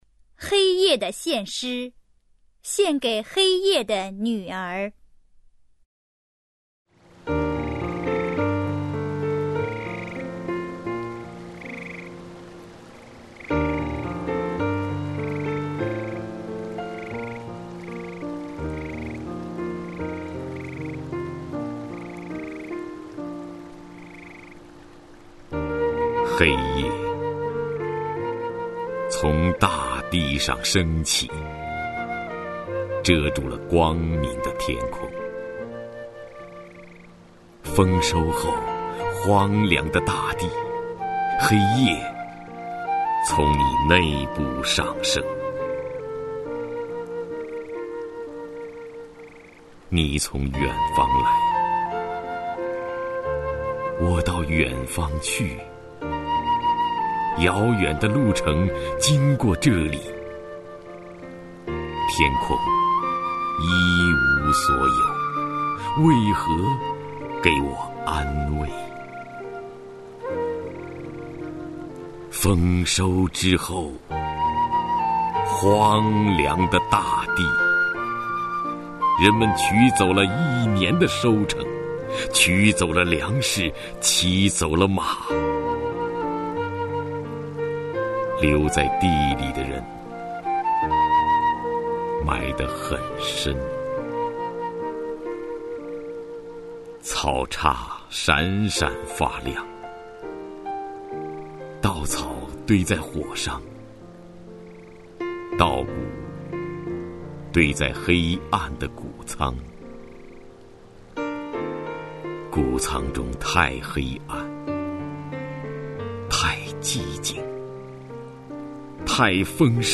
首页 视听 名家朗诵欣赏 徐涛
徐涛朗诵：《黑夜的献诗——献给黑夜的女儿》(海子)